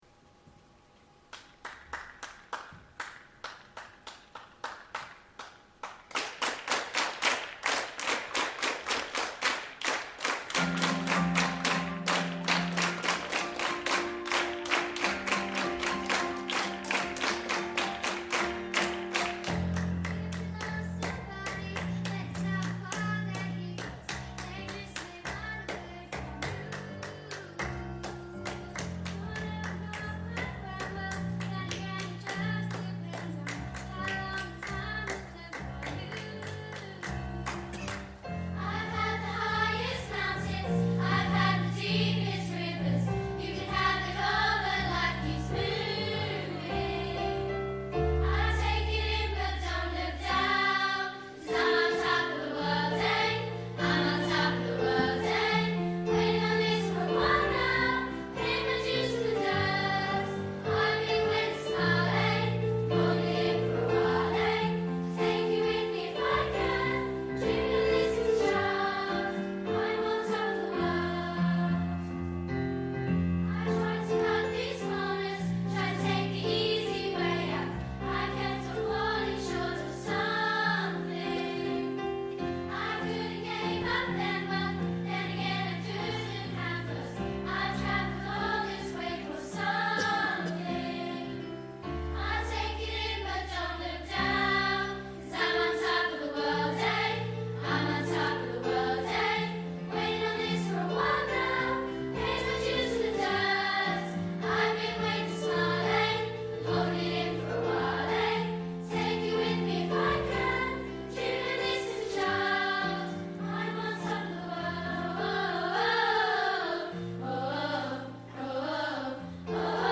Performed at the Autumn Concert, November 2014 at the Broxbourne Civic Hall.